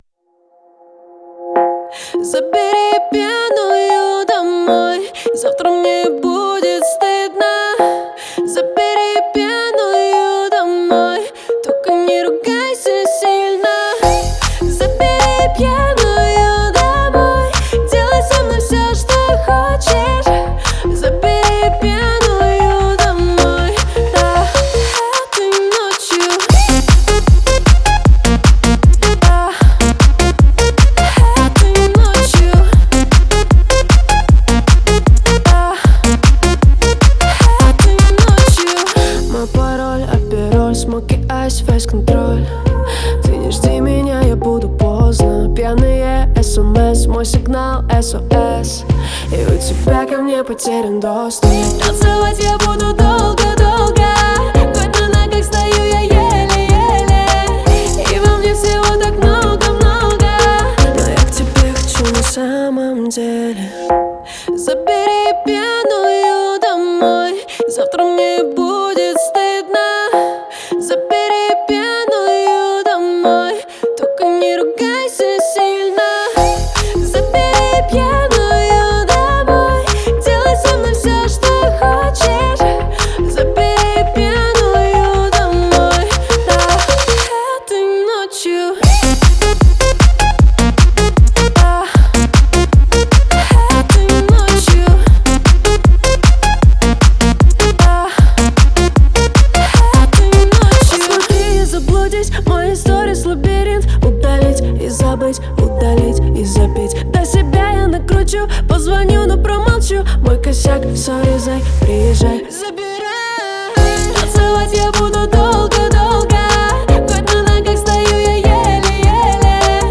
зажигательная поп-песня